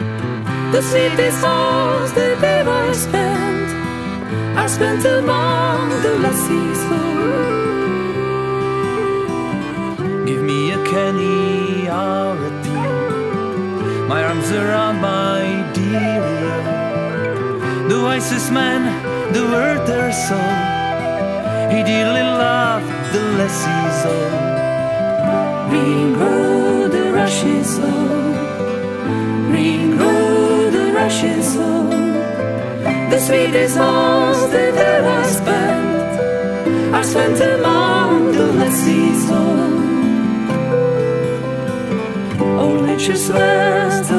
Irská tradiční hudba s folkovými prvky
Nahráno a smícháno: 2016 ve studiu Klíč